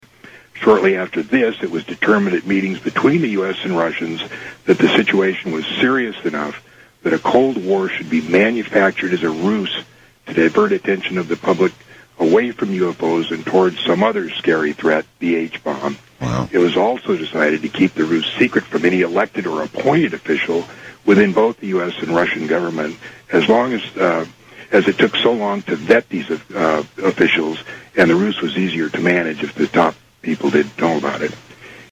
Tags: Art Bell Art Bell interview John Lear UFO Aliens